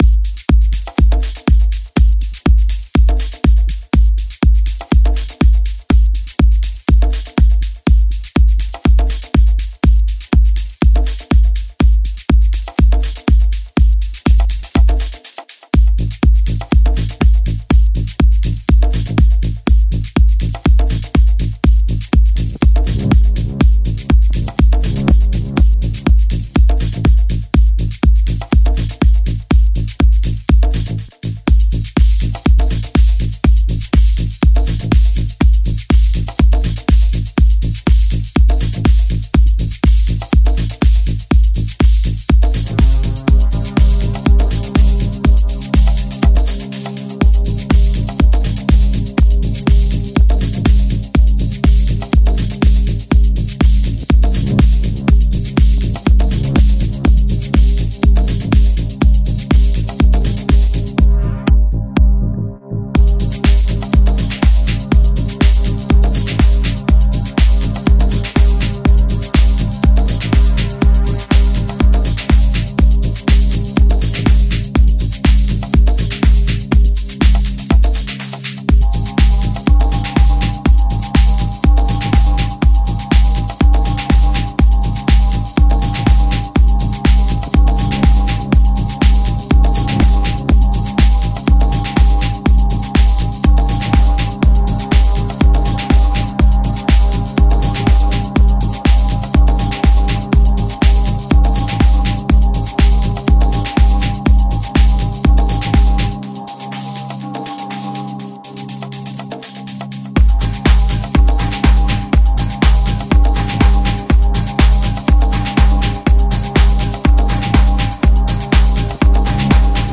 EP
Genre: Progressive House , Deep House